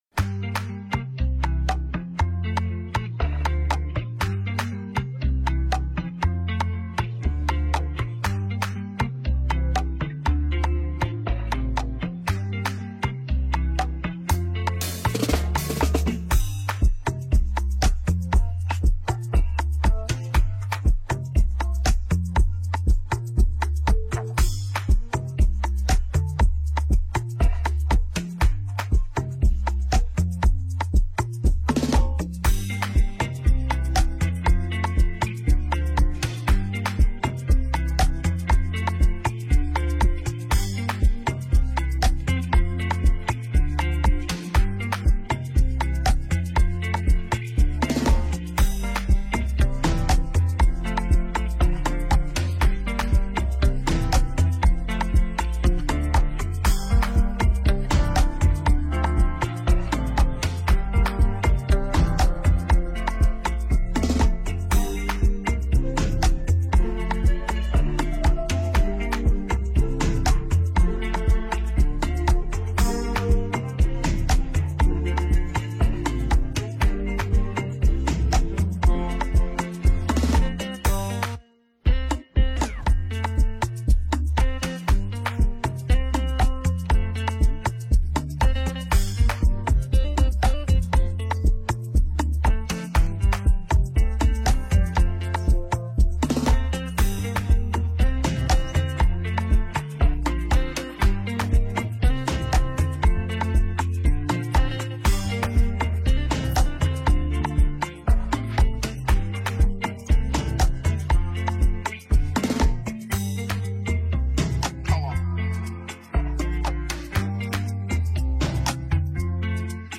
infused with highlife undertones and intricate guitar motifs
gritty and confident bars